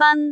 speech
cantonese
syllable
pronunciation
fan1.wav